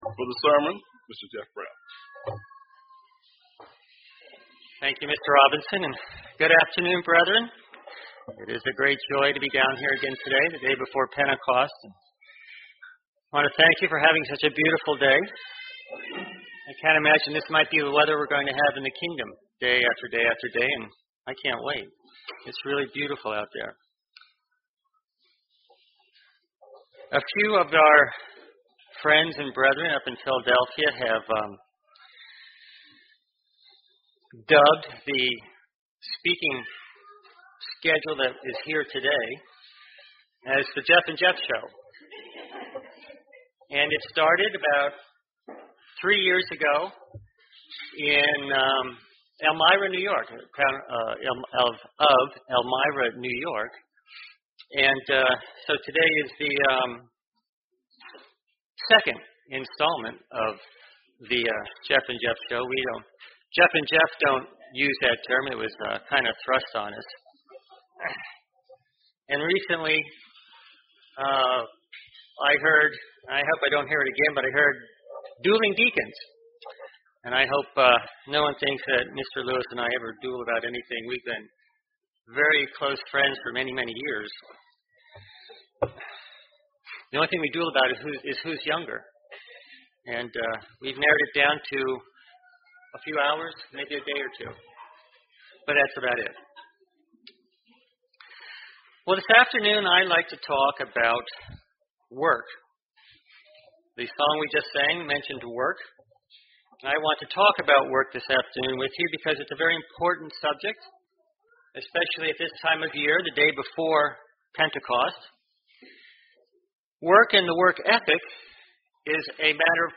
UCG Sermon Studying the bible?
Given in Columbia, MD